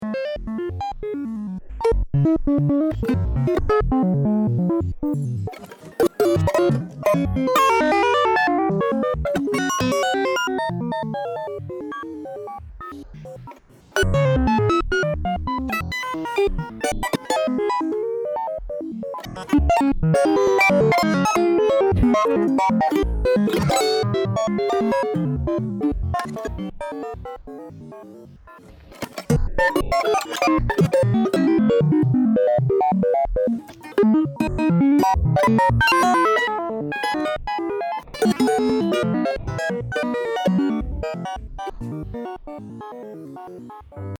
An amalgam of sound and music